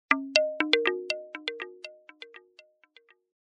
Pebbles.mp3